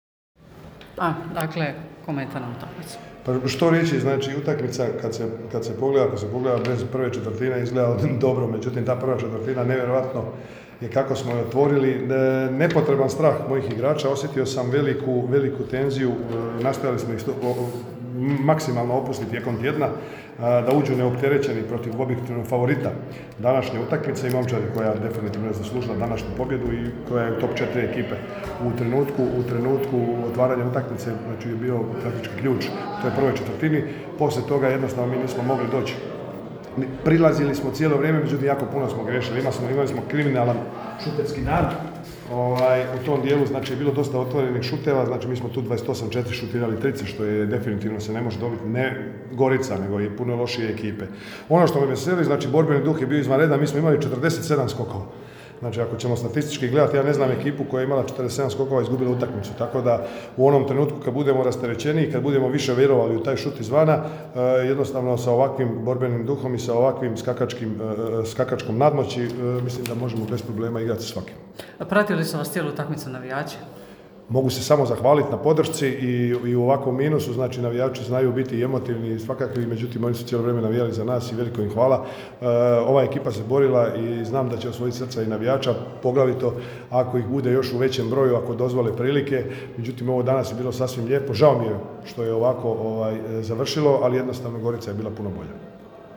IZJAVE: